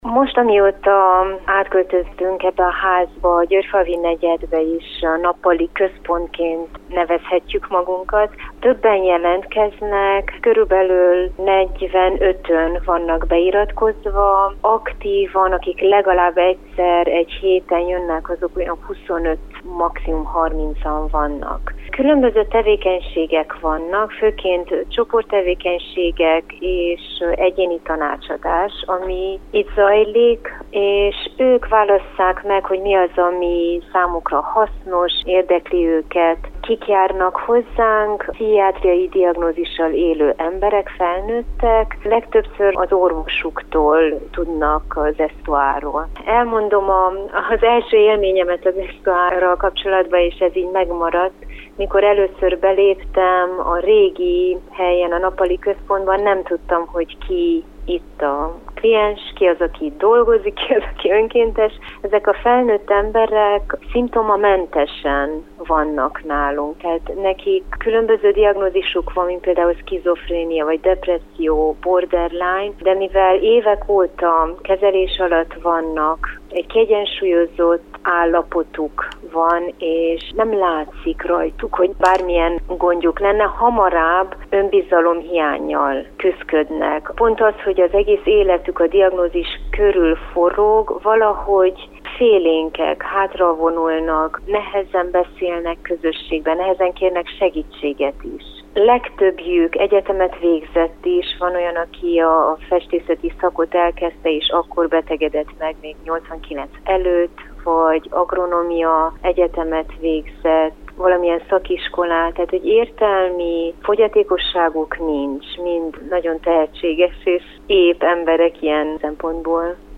Élő Kolozsvári Rádió